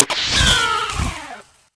Grito de Meowscarada.ogg
Grito_de_Meowscarada.ogg.mp3